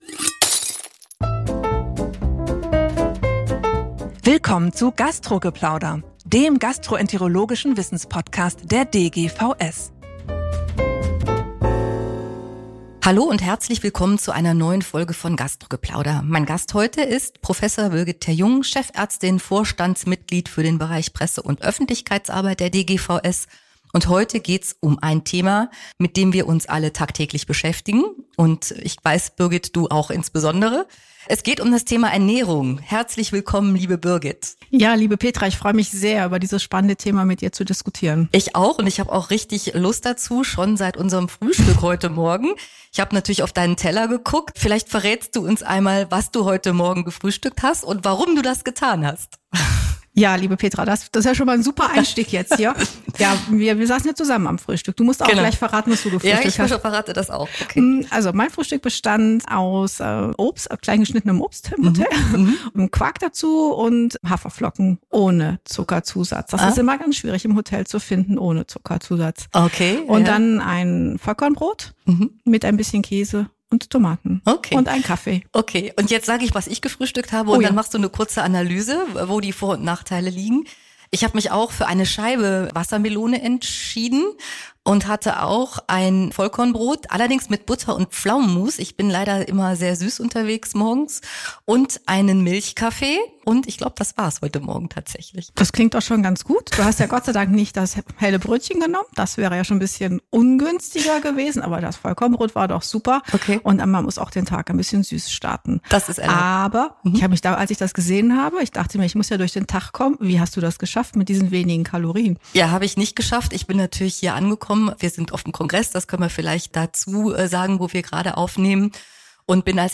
Darmgesundheit beginnt auf dem Teller ~ GASTRO GEPLAUDER: Der gastroenterologische Wissens-Podcast Podcast